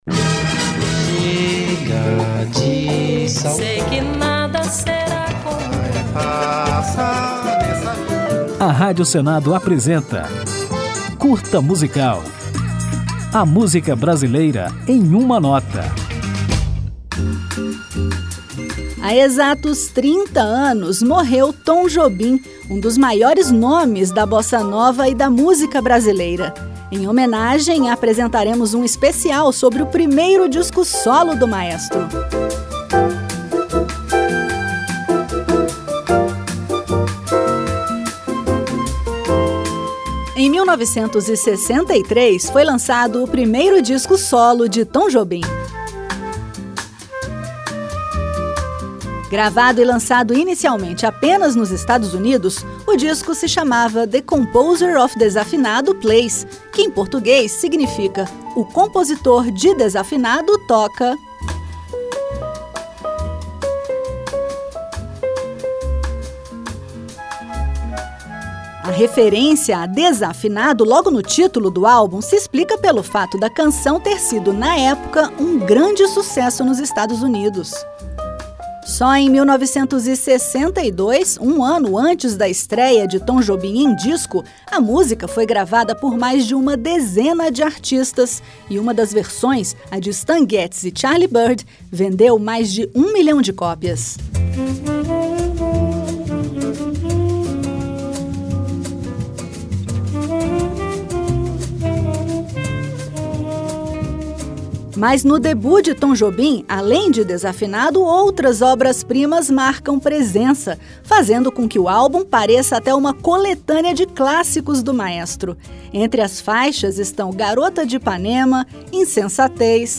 Para marcar a data, o Curta Musical apresenta um especial sobre The Composer of Desafinado Plays, o primeiro álbum solo gravado pelo maestro, pianista, compositor e arranjador. E é desse disco a versão de Desafinado que toca ao final do programa.